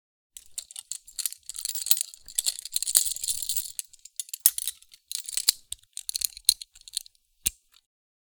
Download Free Prison Sound Effects | Gfx Sounds
Prisoner-handcuffs-movement-handling-rattle-6.mp3